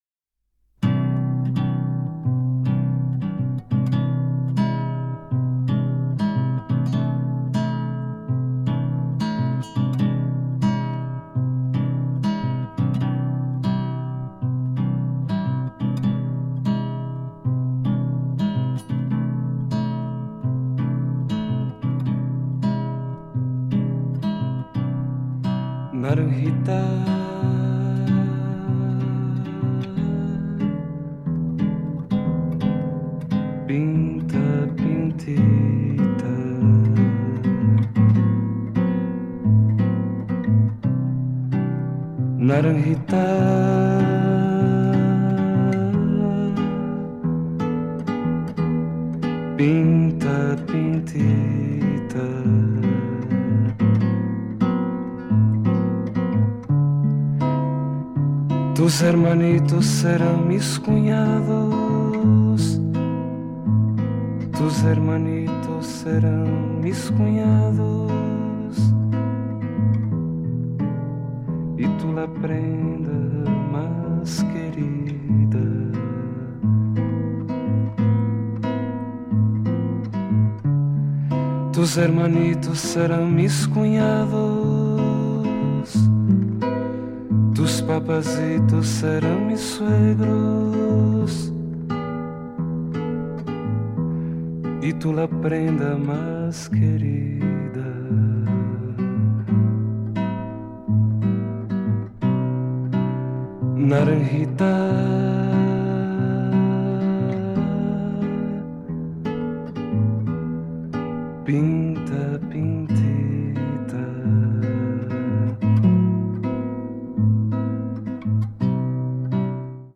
静けさを孕んだサイケデリックな音像が退廃的にも響く傑作です！